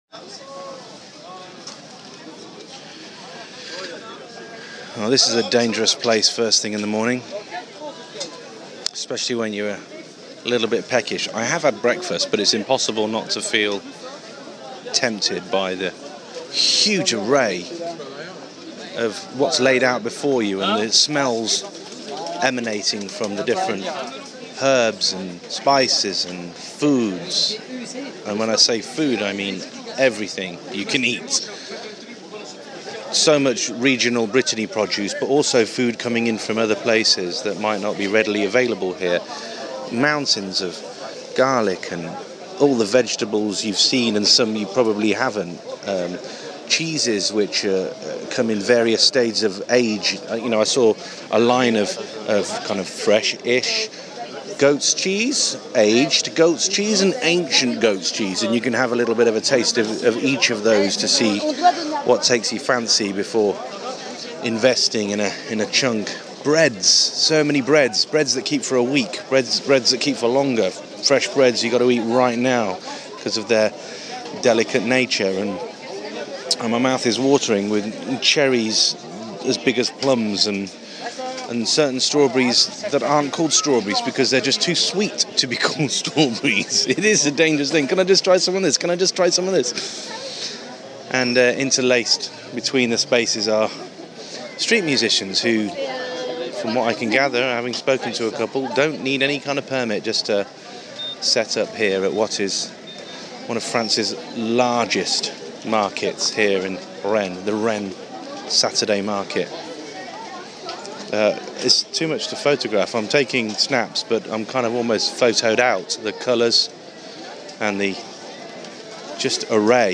Rennes Saturday Market